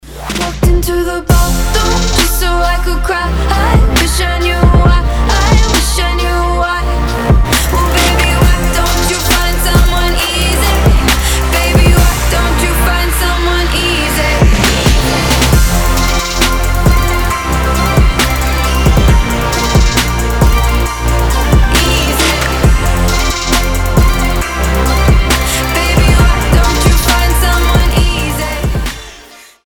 • Качество: 320, Stereo
мелодичные
Electronic
Trap
красивый женский голос
future bass